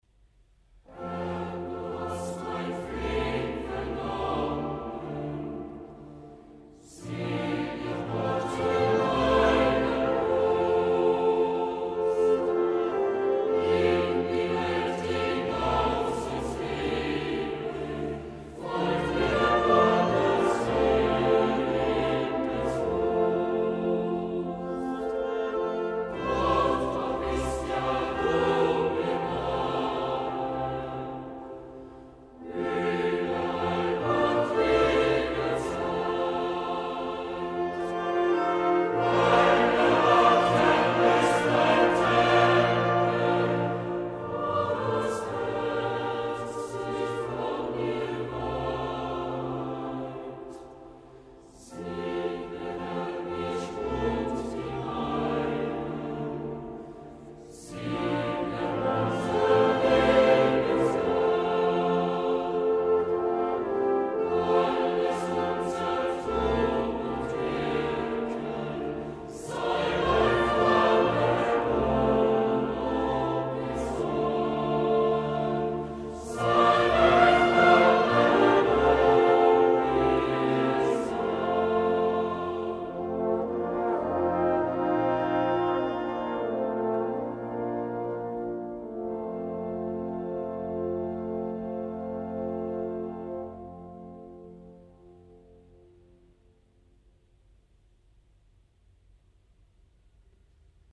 1 Koor van St Augustinus in Wenen
Choir St Augustin Vienna - 7 - Schluszgesang.mp3